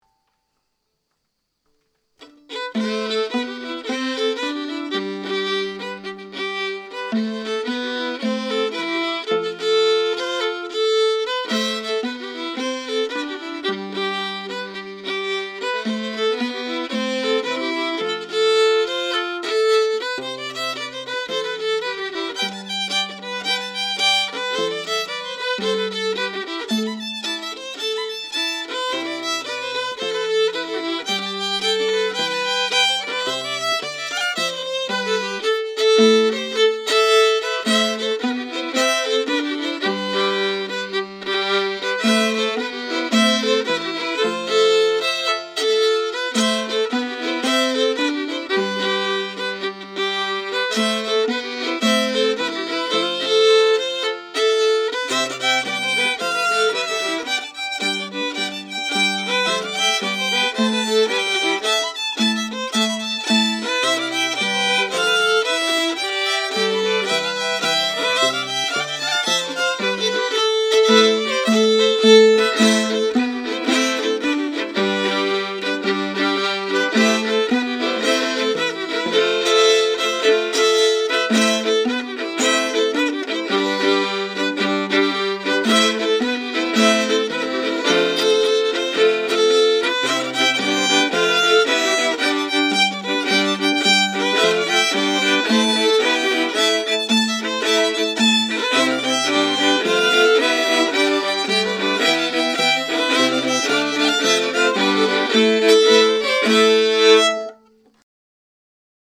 Key: C
Form: Jig
Four part arrangement for VFO
Genre/Style: Morris Dance jig